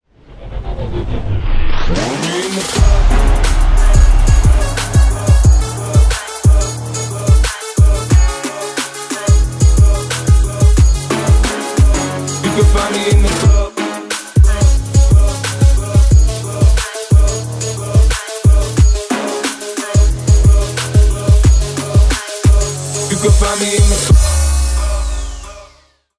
Ремикс
клубные
без слов